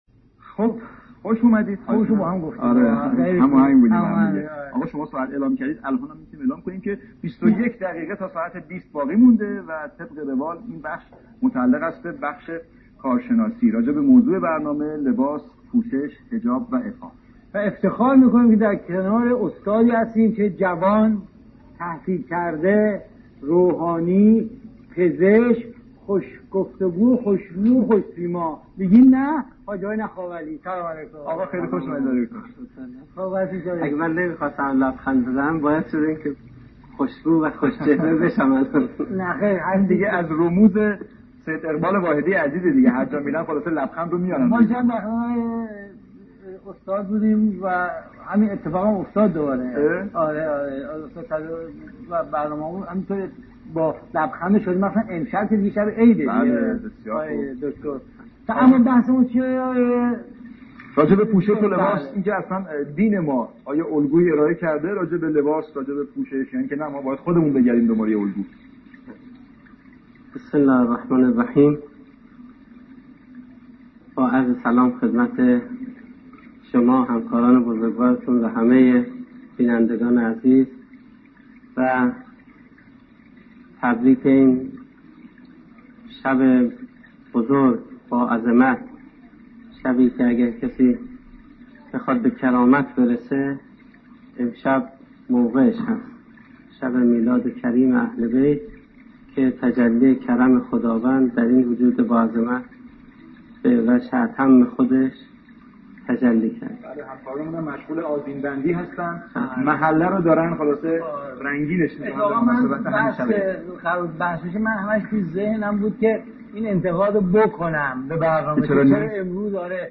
گفت و گوی زنده